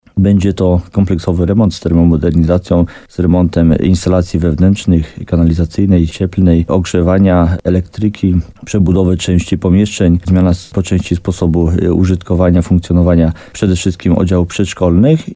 Jak mówi wójt Jacek Migacz to ogromny koszt dla samorządu, ale wszystkie zaplanowane prace są konieczne.